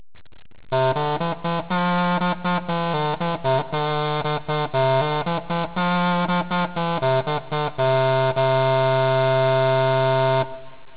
Bass cornamuse - Wav
The bass cornamuse has a wonderfully deep, resonating tone which you can hear on several tracks on my Early Music CD. The two sound clips available for download above illustrate the difference in pitch between the two instruments.